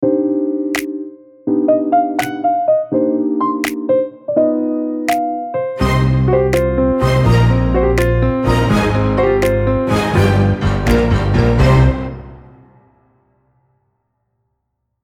cool-jazz-logo.mp3